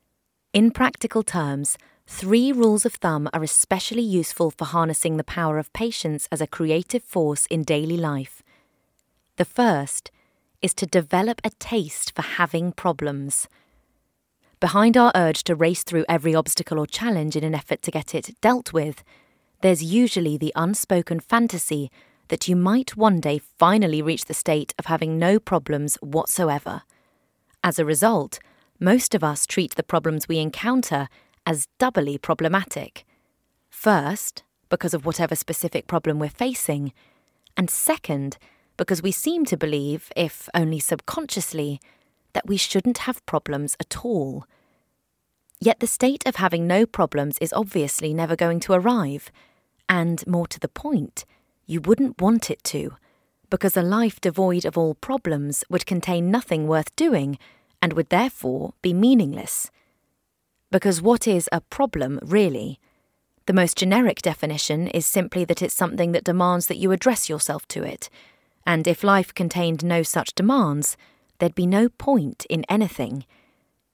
Steely, strong and engaging.
Good accents, ideal for audiobooks. Home Studio.
Home Studio Read